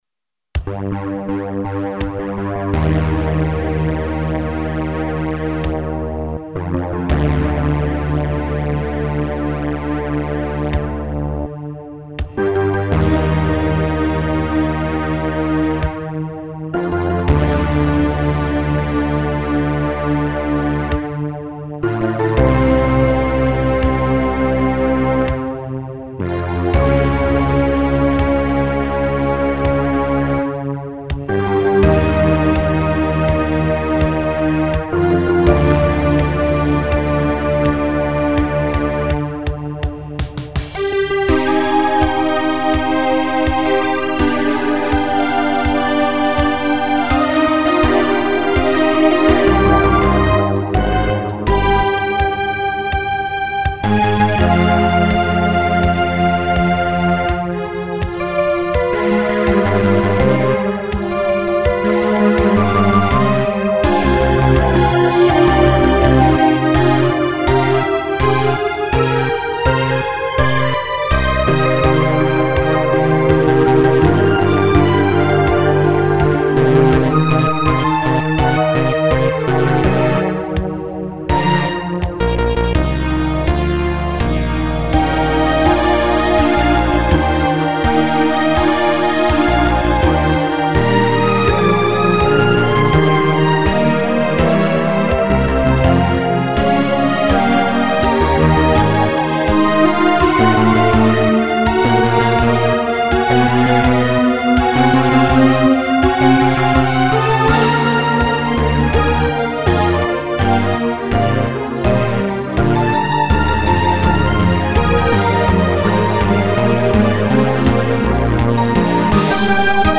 The second version got quite a many new drum hits.